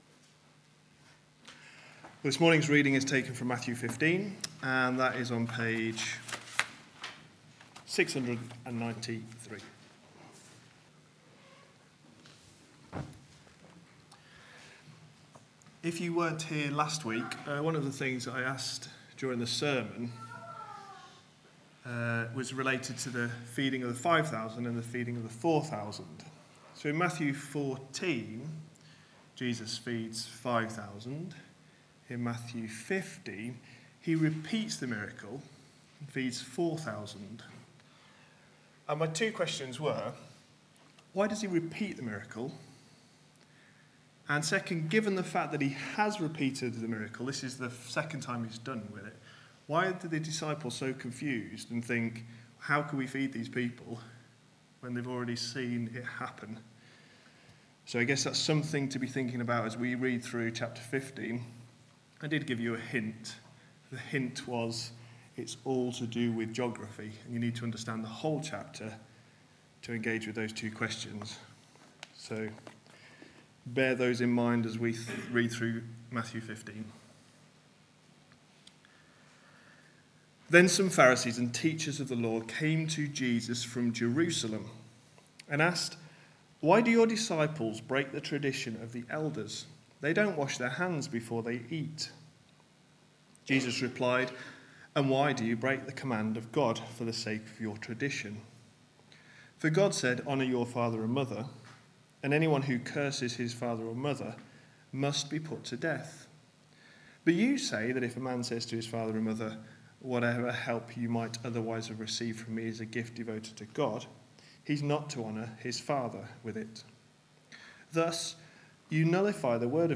A sermon preached on 6th December, 2015, as part of our Matthew series.